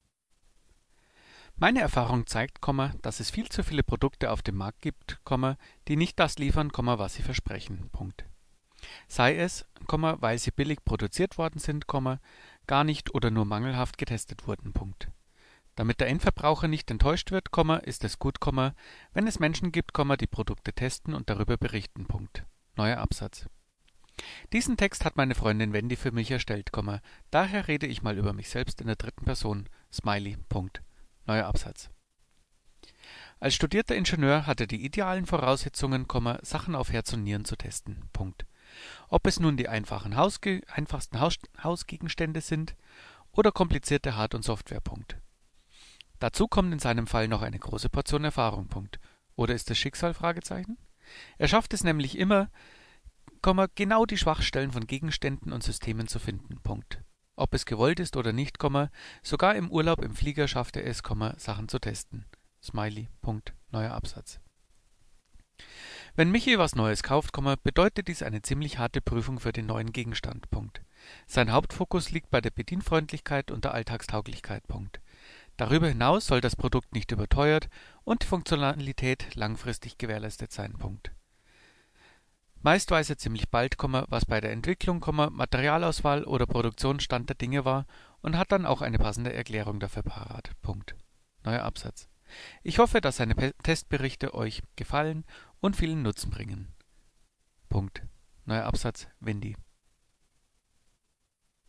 Probetext 1 umsetzen – Tablet mit Nuance-Headset
Selber reinhören: Probetext 1 mit Tablet und Headset
(Aufnahme mit Audio Evolution Mobile: 44100Hz, 16bit, mono; WAV)
• Trotz den nicht optimalen Bedingungen für Laptop und Tablet sind auch hier die Erkennungsraten hoch.
3_Probetext_1_Tablet.mp3